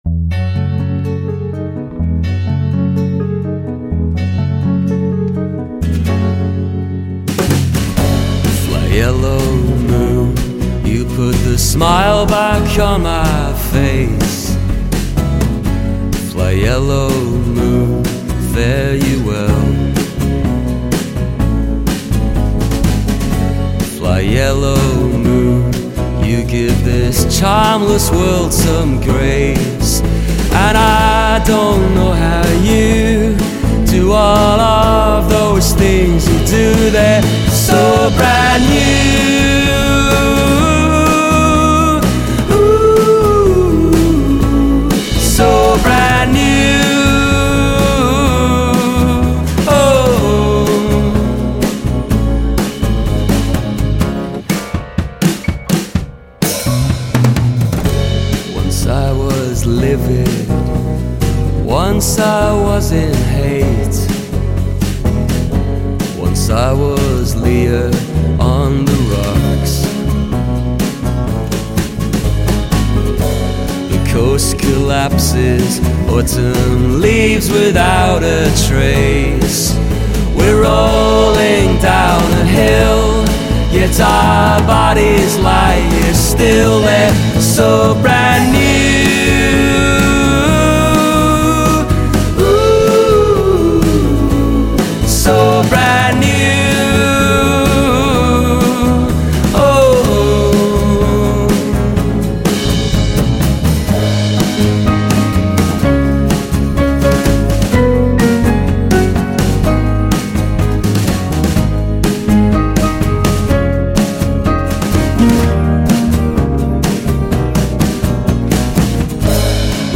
But’s a nice pop song in its own right.